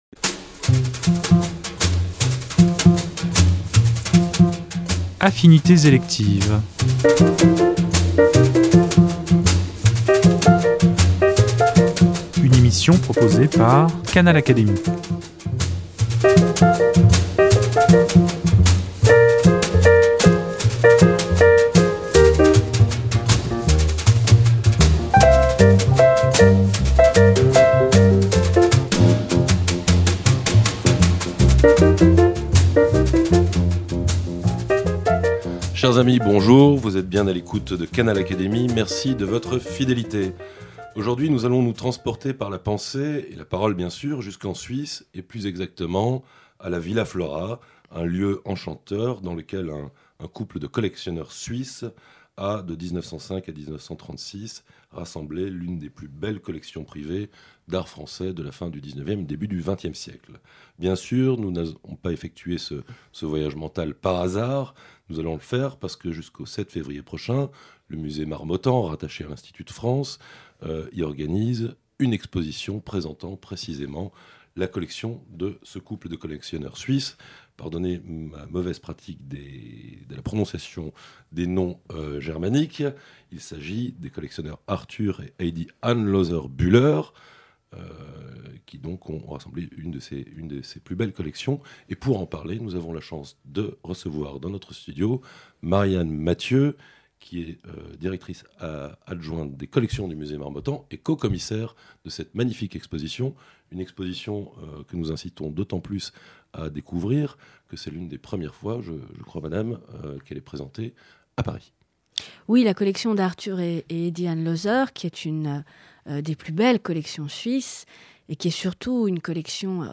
Dans l’entretien qu’elle nous a accordé